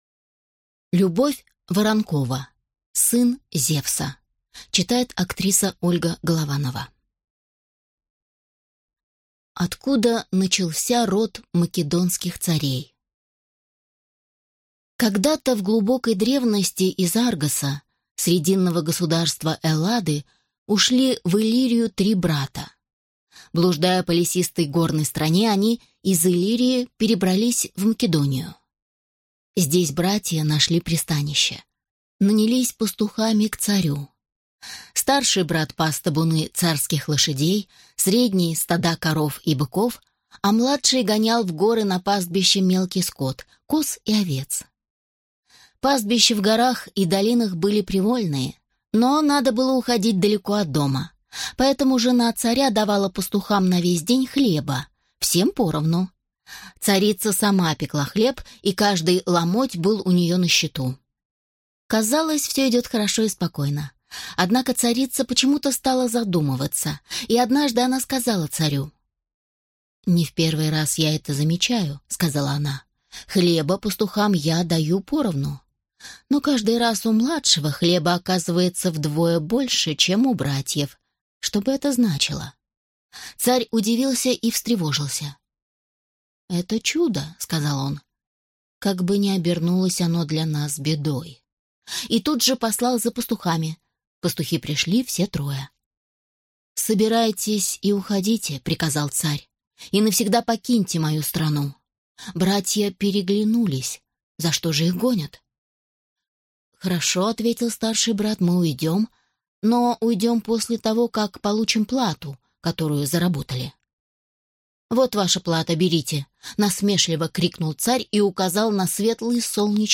Аудиокнига Сын Зевса | Библиотека аудиокниг